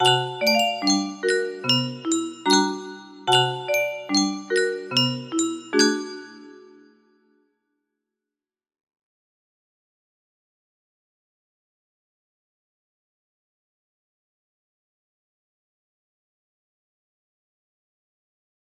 Unknown Artist - Untitled music box melody
Yay! It looks like this melody can be played offline on a 30 note paper strip music box!